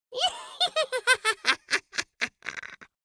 the-sound-of-laughing-witch